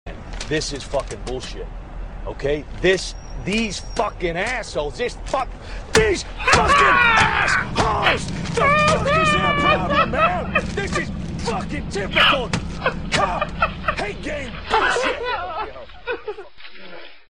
crazy laugh fukn-aholz
crazy-laugh-fukn-aholz_HV2G2KS.mp3